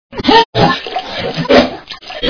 puke_y.wav